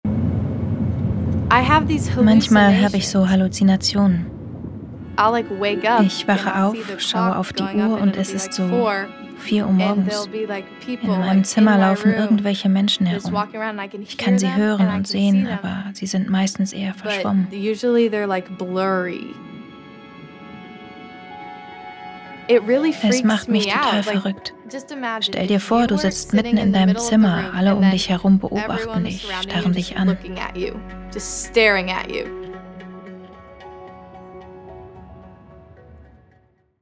hell, fein, zart
Jung (18-30)
Voice Over – NDR „One In A Million“ Dokumentarfilm (2022), Szene 2
Doku